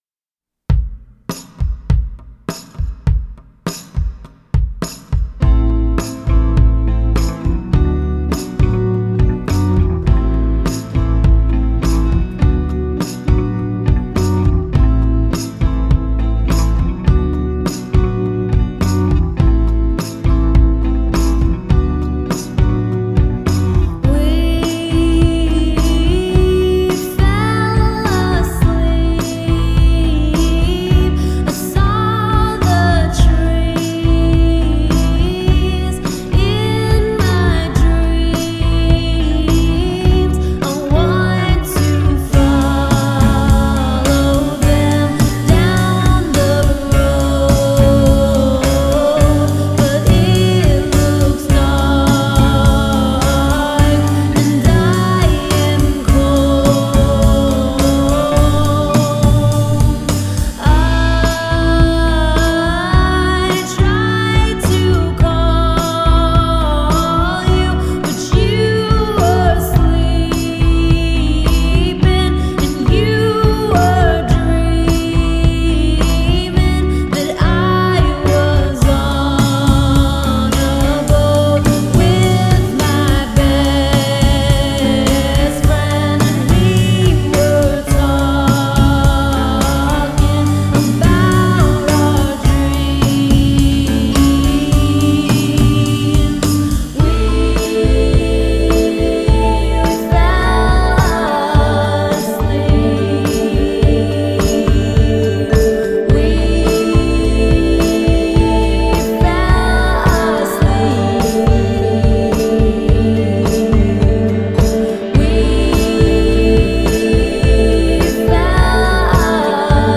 The reverb on her vocals bleed onto tracks like